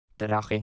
Hanno il suono di una "h" aspirata la consonante -g-, usata nei gruppi -ge, gi-, e la lettera -j- sempre.